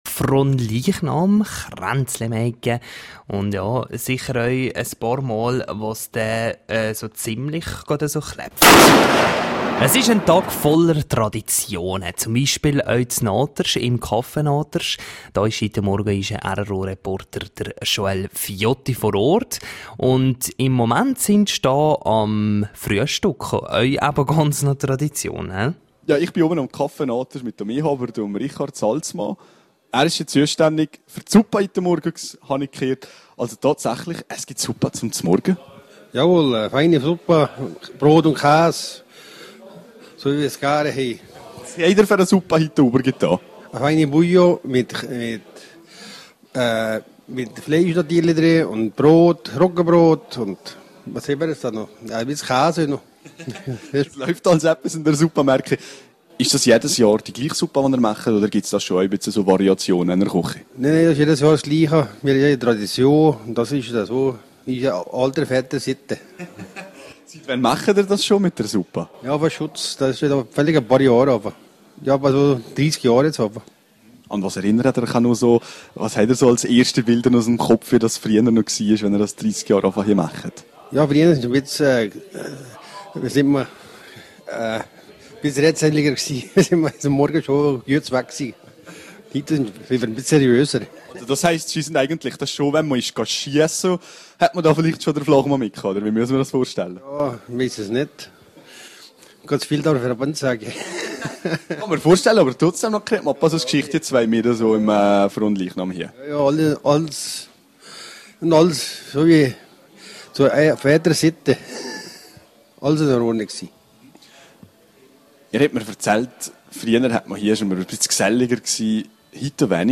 In vielen Oberalliser Dörfern gibt es an Frohnleichnam einige Traditionen. rro war in Naters zu Besuch.